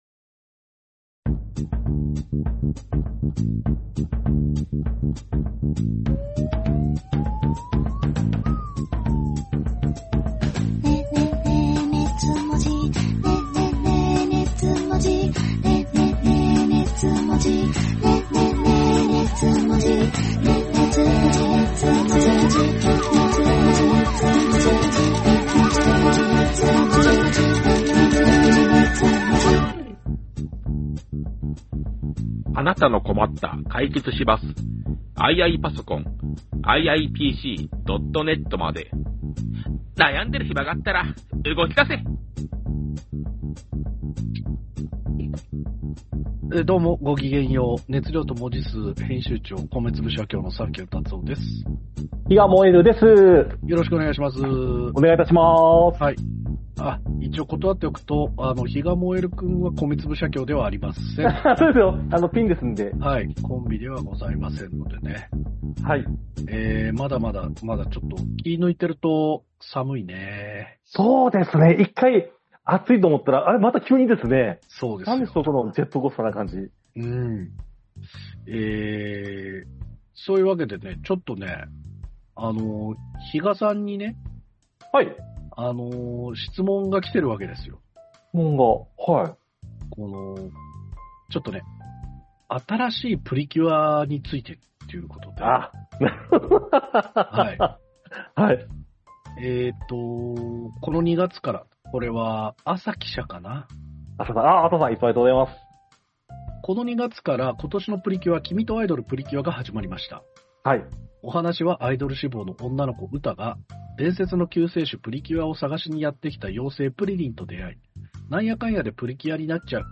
オタク芸人 サンキュータツオ Presents 二次元を哲学するトークバラエティ音声マガジン『熱量と文字数』のブログです。